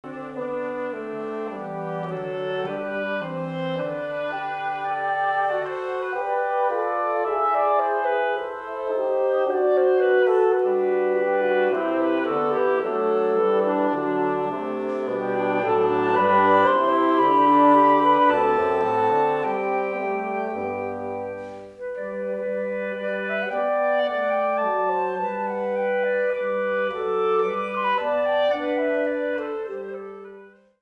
A selection of traditional carols including
Wind Quintet
Bassoon , Clarinet , Flute , Horn , Oboe